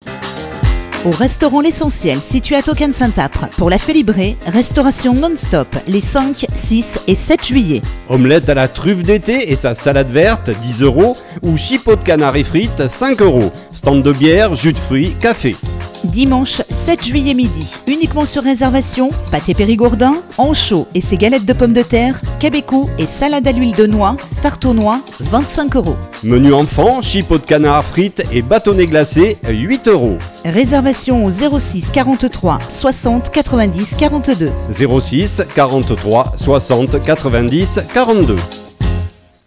réalisé par l'équipe d'Isabelle FM
Spot Pub l'Essentiel.mp3